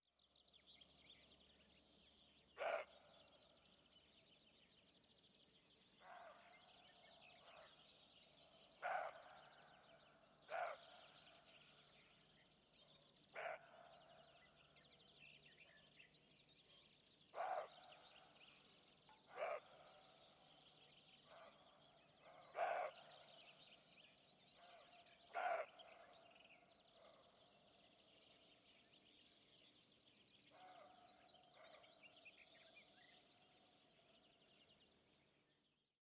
На этой странице собраны натуральные звуки косули — от нежного фырканья до тревожных криков.
Косуля подает голос вдалеке